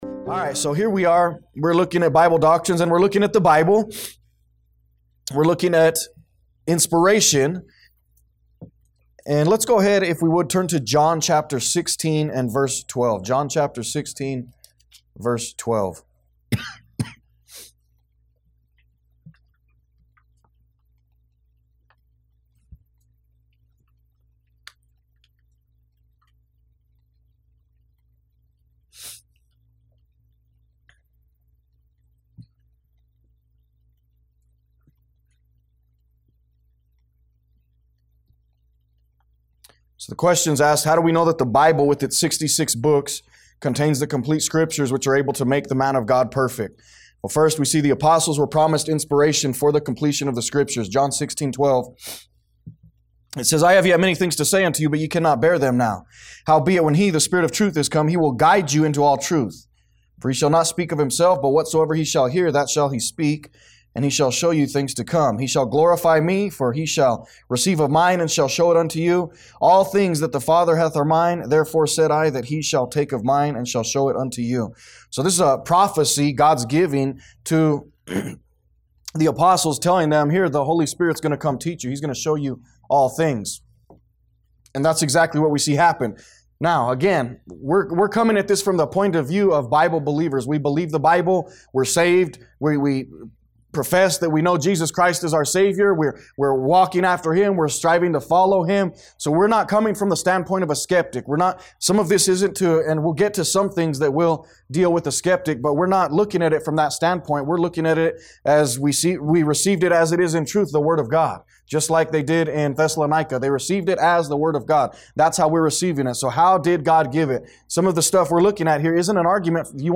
A message from the series "Doctrine of The Bible."